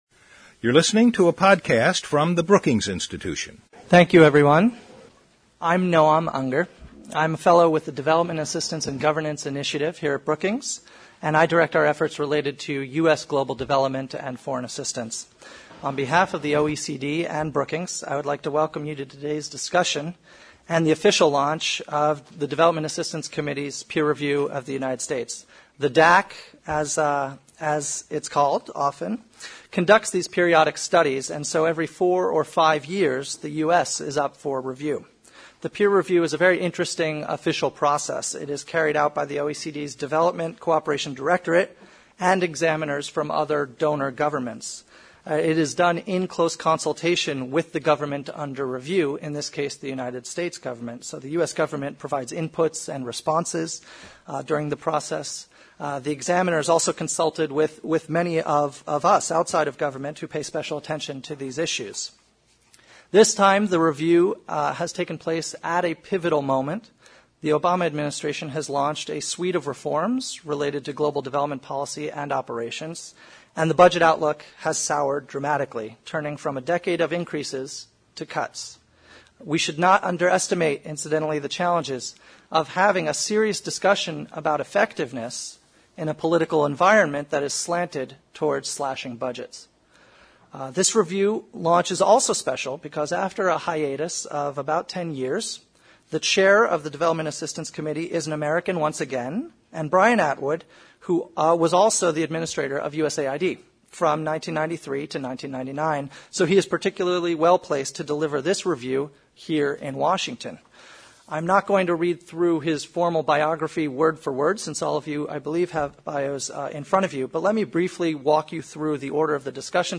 On July 28, Global Economy and Development at Brookings and the Organization for Economic Cooperation and Development (OECD) hosted a discussion on the recently completed peer review of the United States's foreign assistance programs. J. Brian Atwood, chair of the OECD Development Assistance Committee, provided an overview of the peer review’s findings.